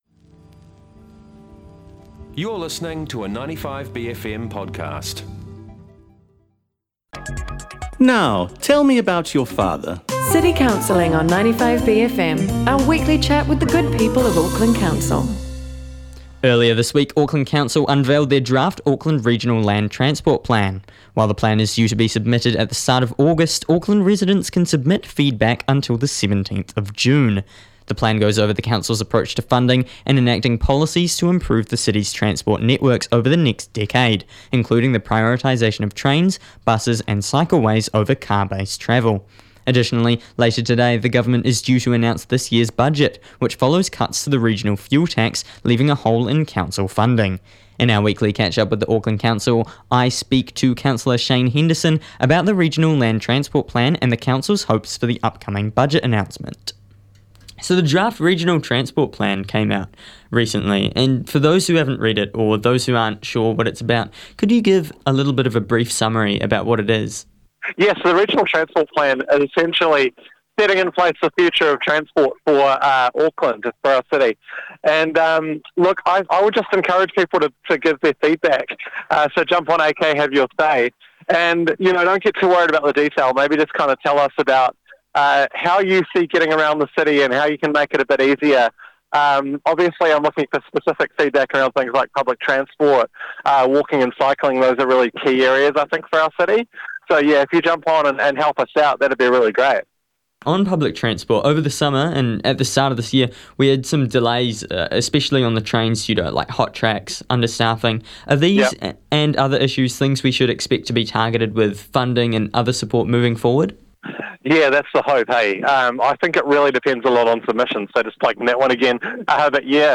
Two alternating Auckland City Councillor's Julie Fairey and Shane Henderson tell us the latest in Council news every Thursday on The Wire.